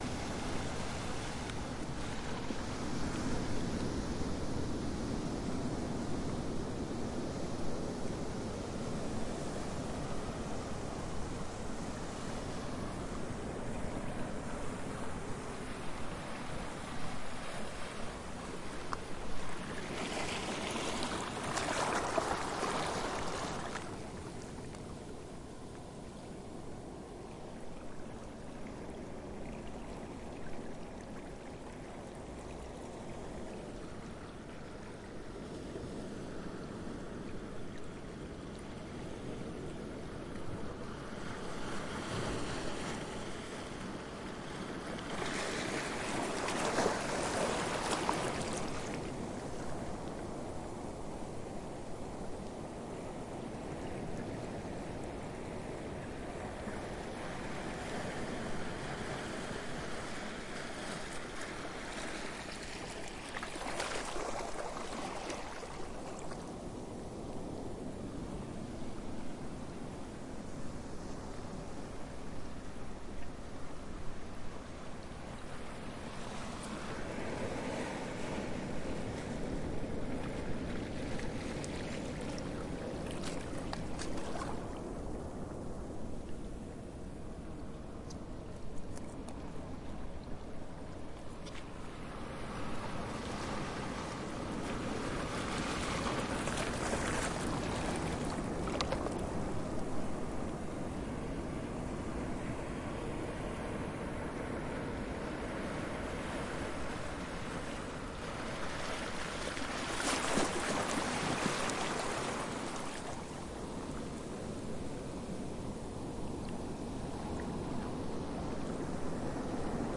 声景 " 海岸上的波浪
描述：关闭碰撞在岩石和沙子上的泡沫和波浪的录音。
Tag: 海岸 海洋 海岸